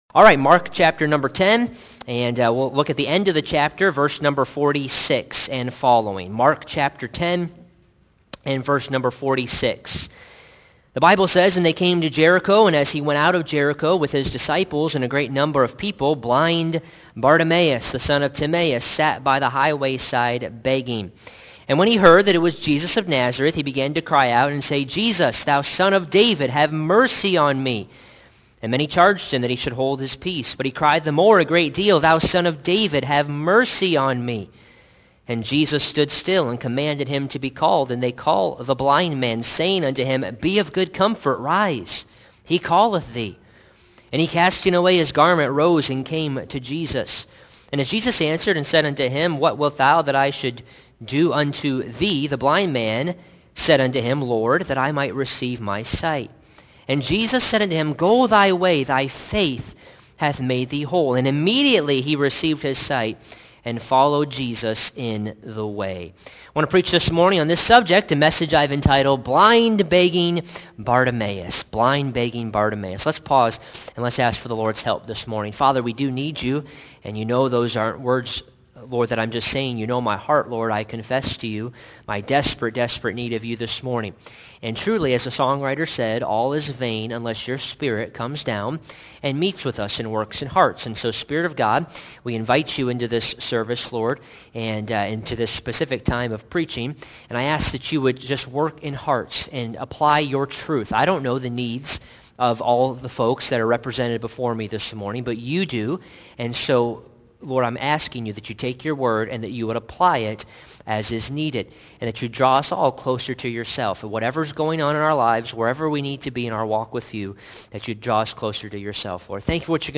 Sermons :: First Baptist of Rochelle